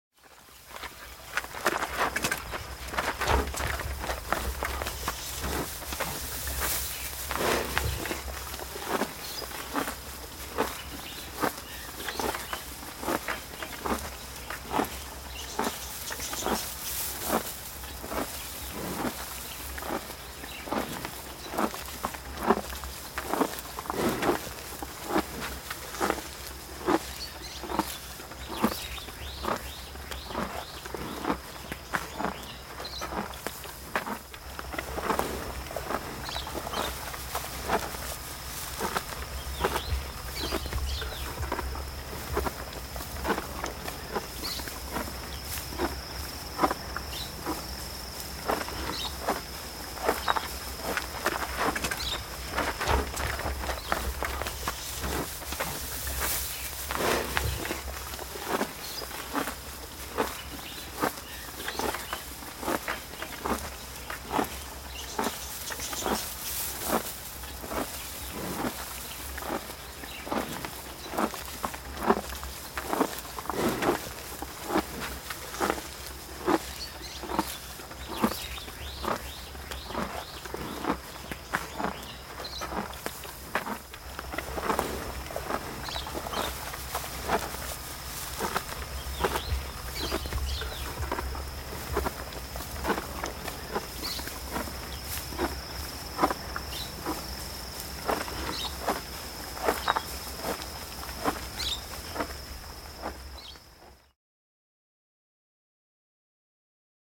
جلوه های صوتی
دانلود صدای کرگدن 3 از ساعد نیوز با لینک مستقیم و کیفیت بالا